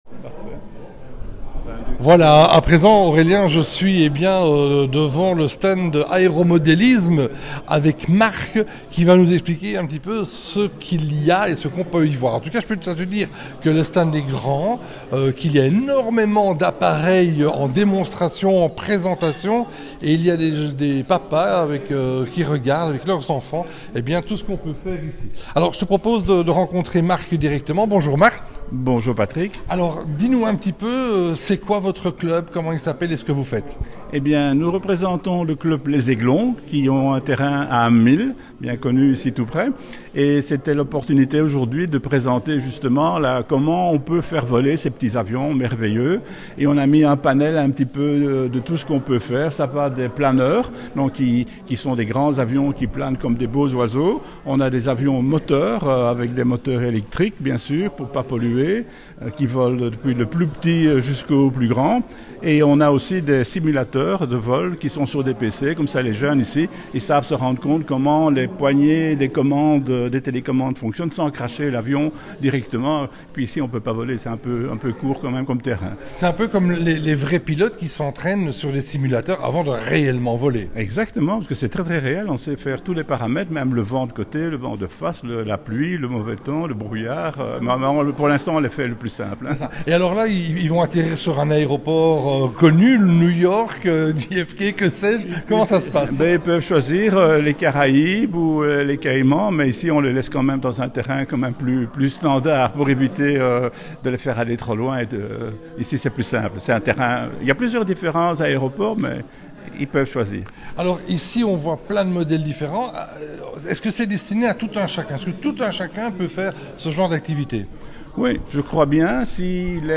30 août 2025, salle du "Vert Galant", Beauvechain